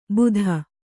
♪ budha